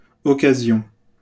wymowa:
IPA[ɔ.ka.zjɔ̃] ?/i